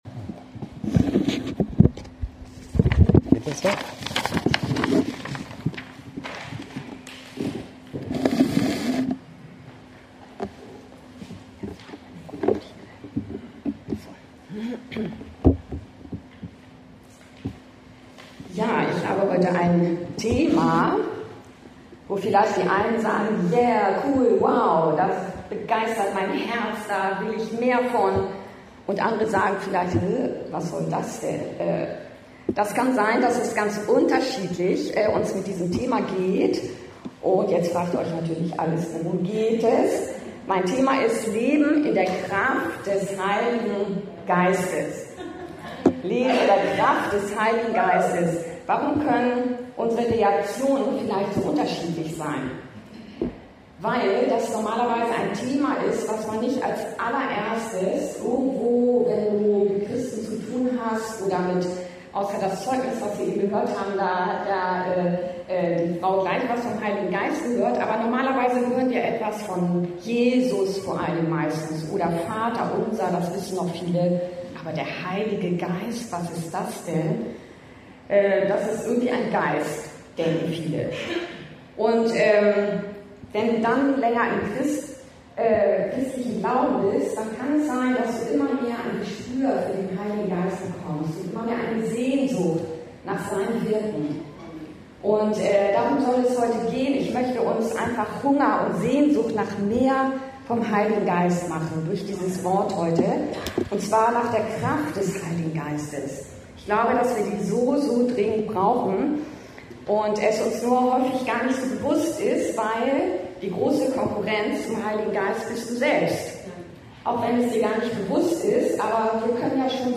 Leben in der Kraft des Heiligen Geistes! ~ Anskar-Kirche Hamburg- Predigten Podcast